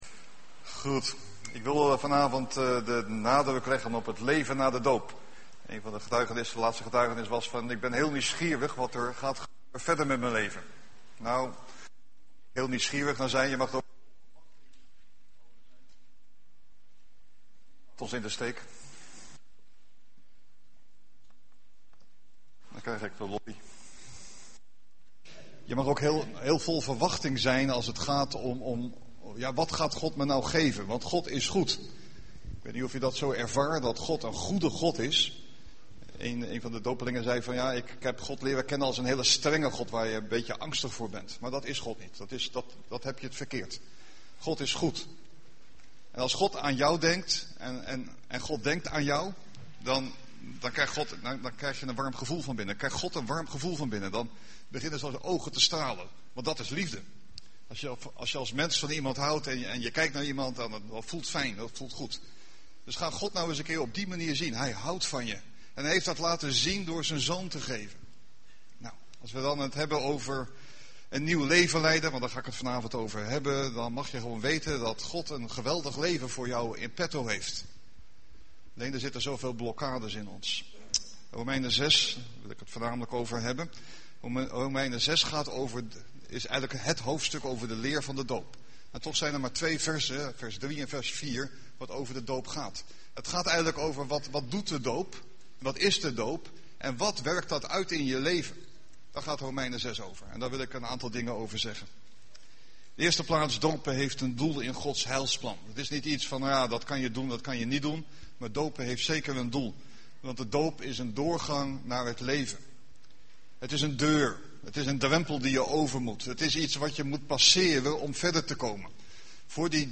Doopdienst 28 augustus